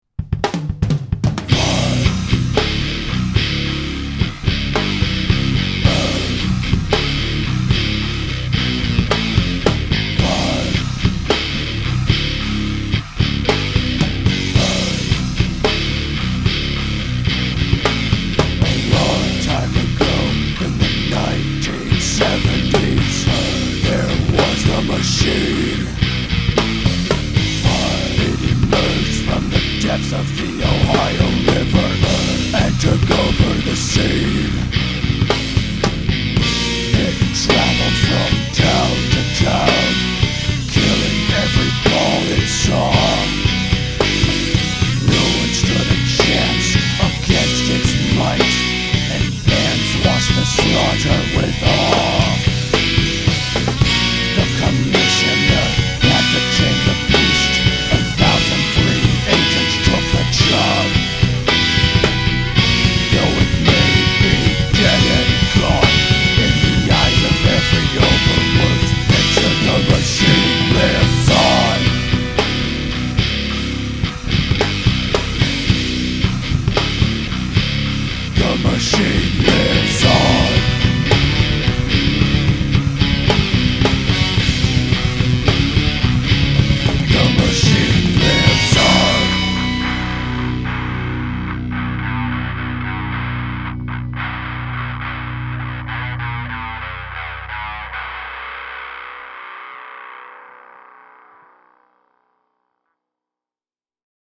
Bask in the heaviness! And try not to hit your keyboard while headbanging (can’t say I don’t look out for you people).